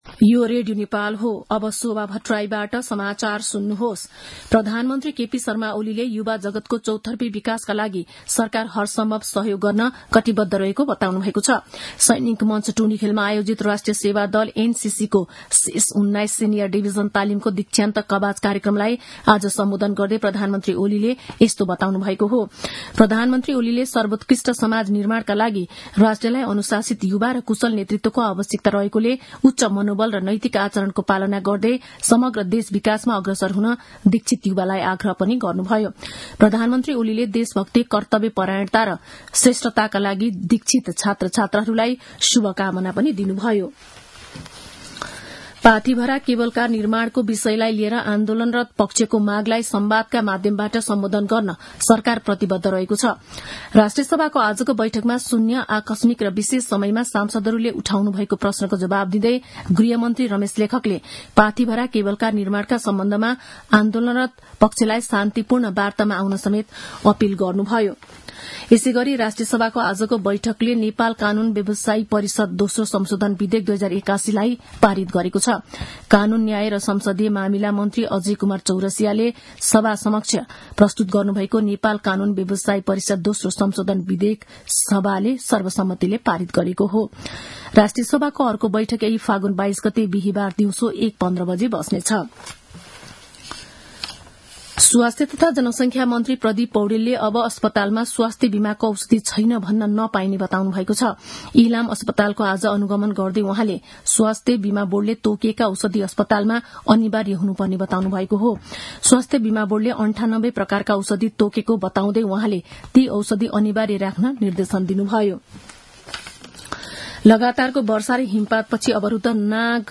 दिउँसो ४ बजेको नेपाली समाचार : १९ फागुन , २०८१
4-pm-news-.mp3